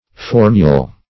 formule - definition of formule - synonyms, pronunciation, spelling from Free Dictionary Search Result for " formule" : The Collaborative International Dictionary of English v.0.48: Formule \For"mule\, n. [F.]